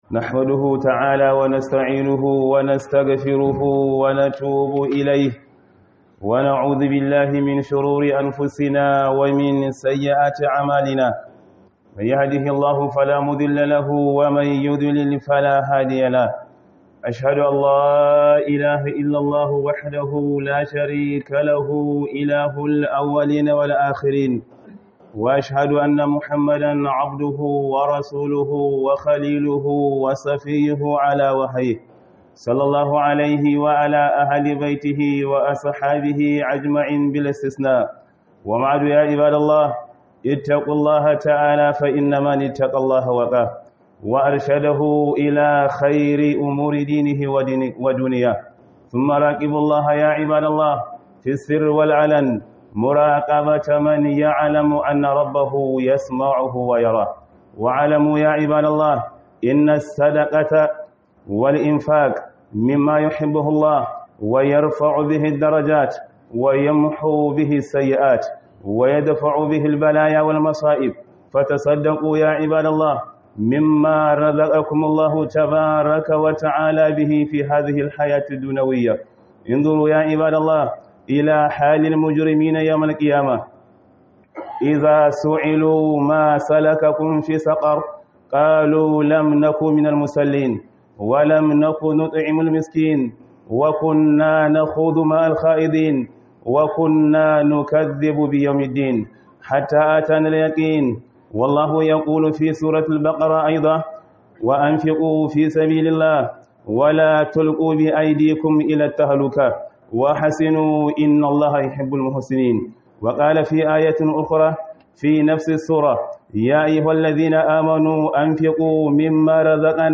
KHUDUBAR JUMA'A
Khuduba